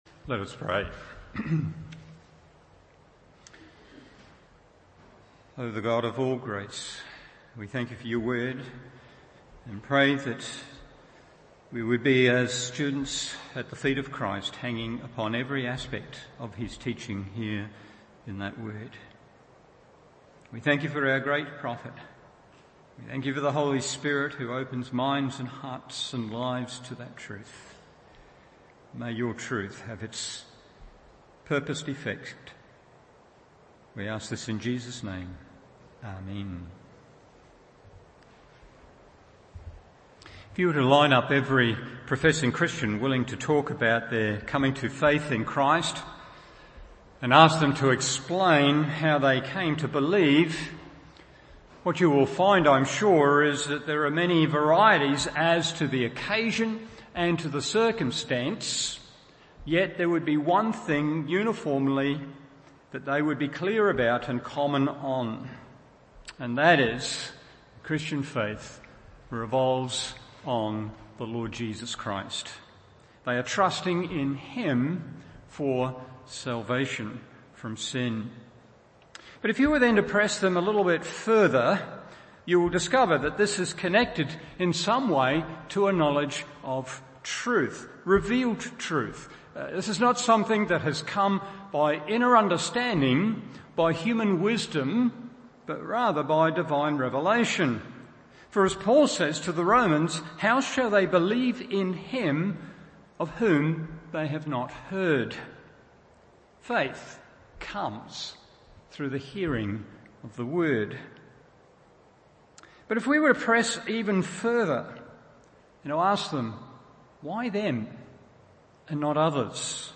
Evening Service Acts 16:11-15 1.